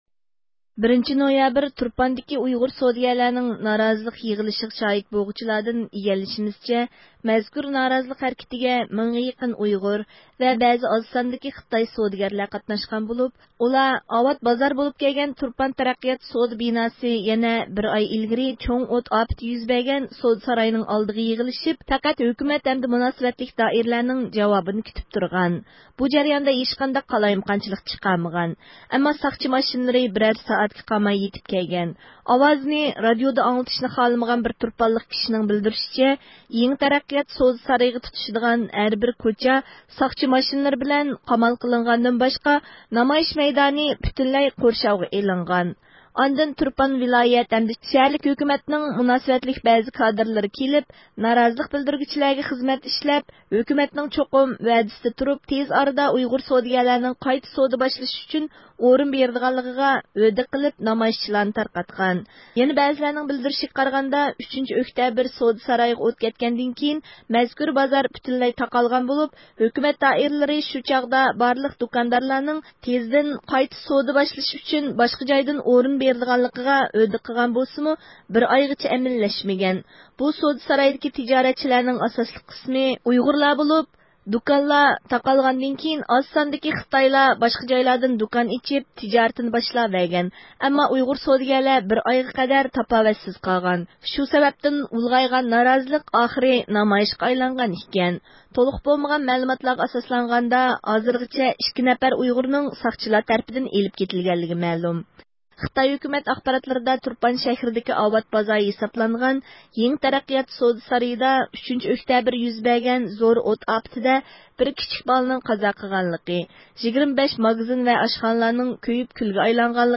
دەسلەپ تۇرپان ۋىلايەتلىك ساقچى ئىدارىسىنىڭ جىنايى ئىشلار ئىشخانىسىغا تېلېفون ئۇلىدۇق.
بىز يەنە تۇرپان شەھەرلىك خەلق ئىشلىرى ئىدارىسىغا تېلېفون قىلدۇق، مۇناسىۋەتلىك بىر كادىر ۋەقەدىن خەۋىرى يوقلۇقىنى بىلدۈردى.